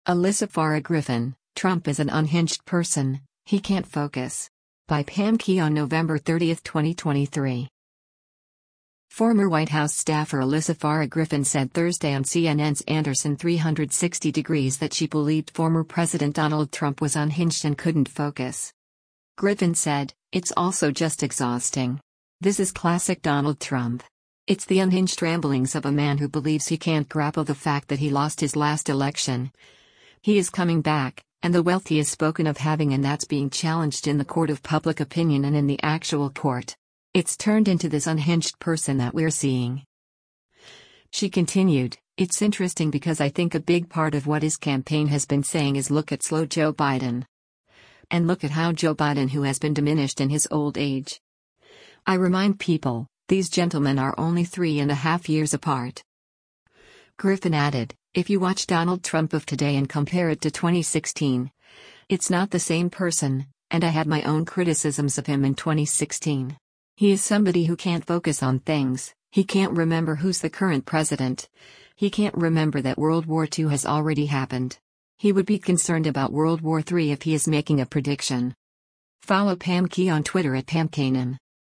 Former White House staffer Alyssa Farah Griffin said Thursday on CNN’s “Anderson 360°” that she believed former President Donald Trump was “unhinged” and couldn’t focus.